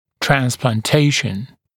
[ˌtrænsplɑːn’teɪʃn] [ˌtrɑːn-][ˌтрэнспла:н’тэйшн], [ˌтра:н-]трансплантация, пересадка органов или тканей